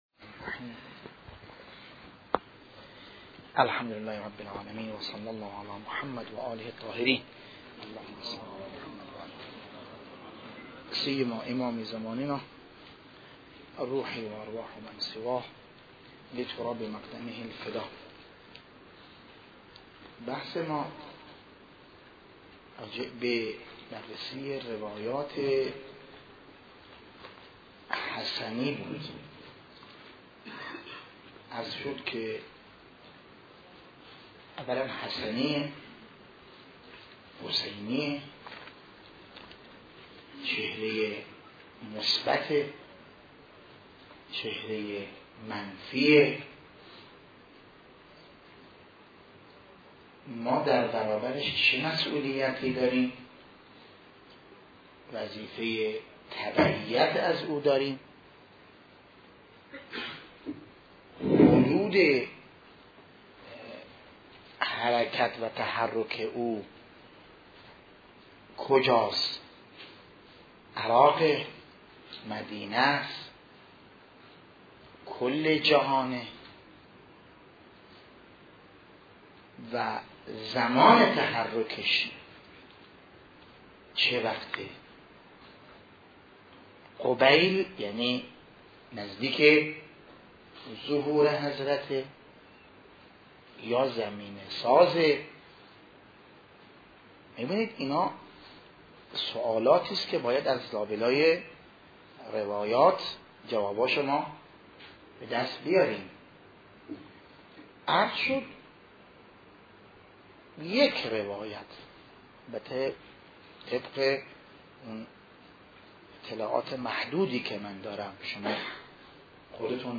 بحث خارج مهدویت - بحث سید حسنی ج 2